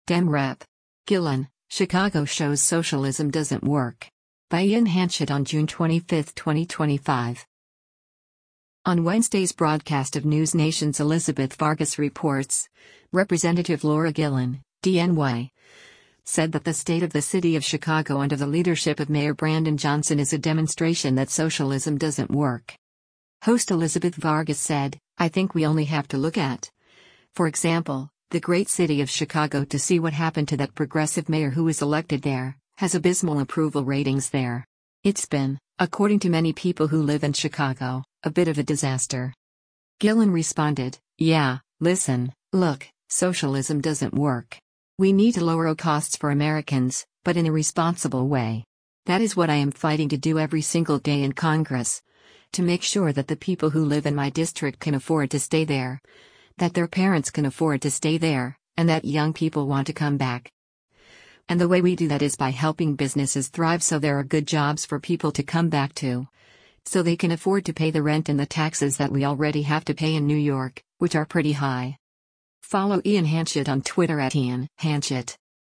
On Wednesday’s broadcast of NewsNation’s “Elizabeth Vargas Reports,” Rep. Laura Gillen (D-NY) said that the state of the city of Chicago under the leadership of Mayor Brandon Johnson is a demonstration that “socialism doesn’t work.”